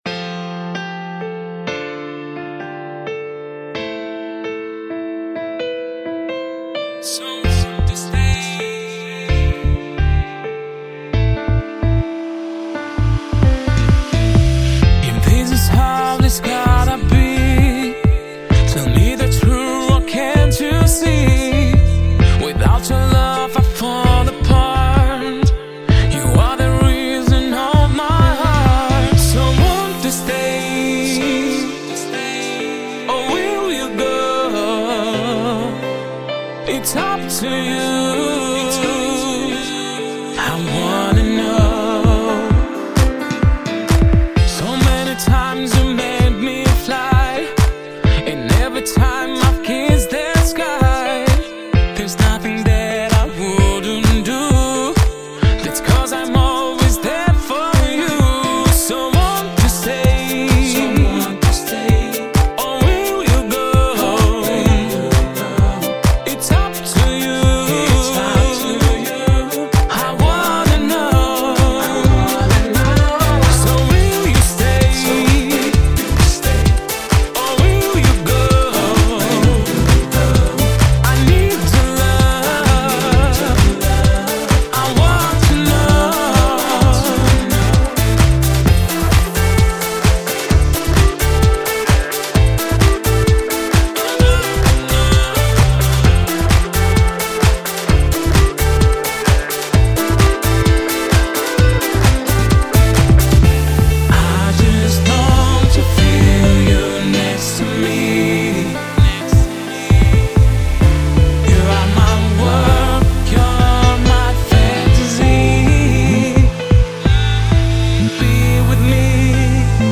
это эмоциональный трек в жанре поп